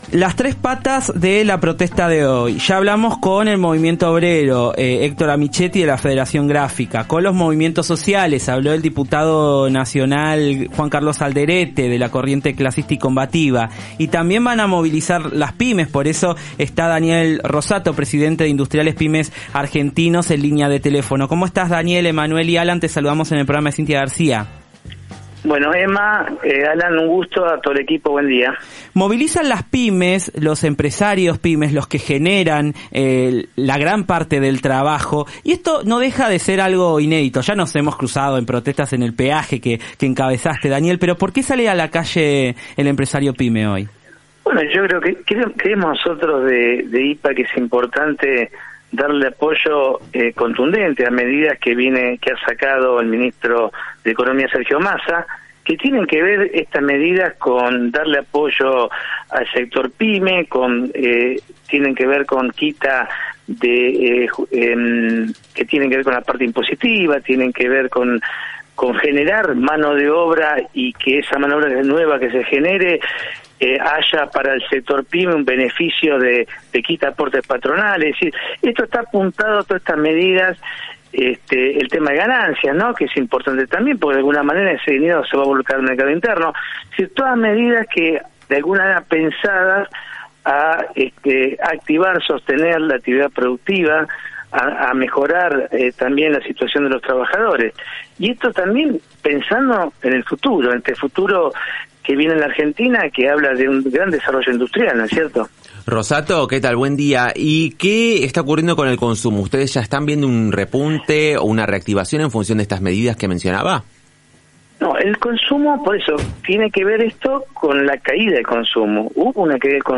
En dialogo con La Garcia por AM75O.